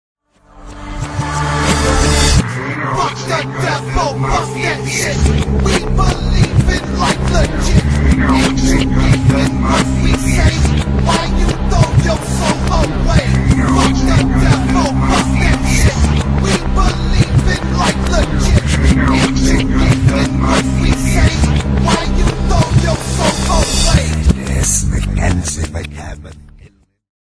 horrorcore